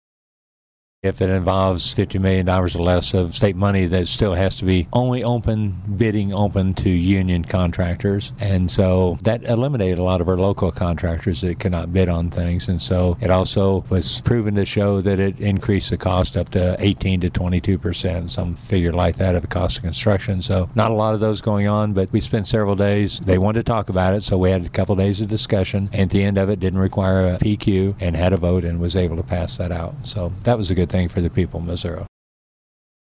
3. Senator Cunningham also says the Missouri Senate is moving toward passing Senate Bill 182, which would make changes to certain project labor agreements in our state.